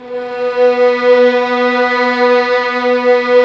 Index of /90_sSampleCDs/Roland LCDP13 String Sections/STR_Violins III/STR_Vls6 mf%f St